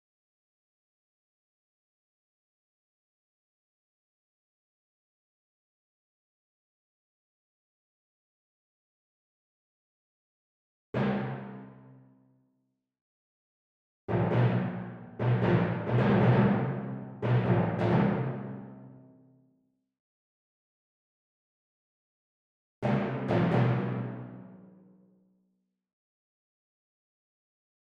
18. Timpani (Timpani/Long hits)
Holst-Jupiter-mod-43-Timpani_0.mp3